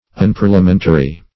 Unparliamentary \Un*par`lia*men"ta*ry\, a.